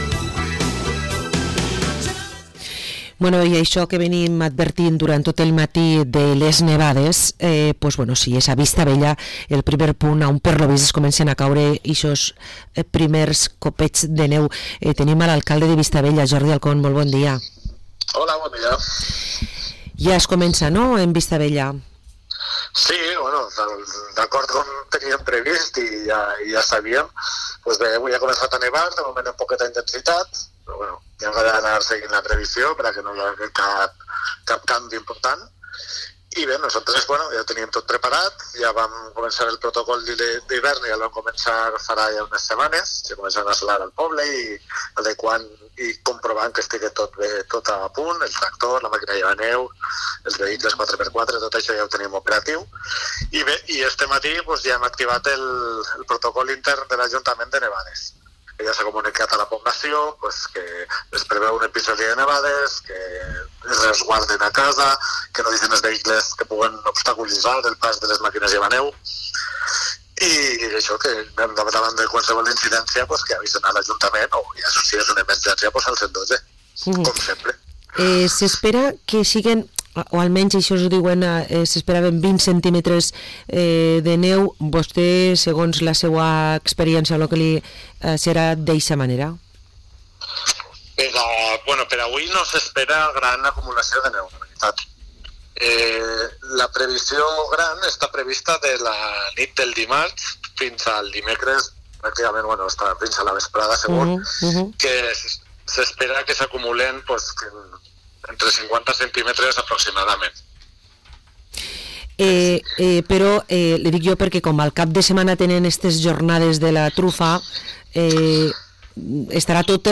Jordi Alcon, alcalde de Vistabella, ens conta que ja tenim els primers flocs de neu en Penyagolosa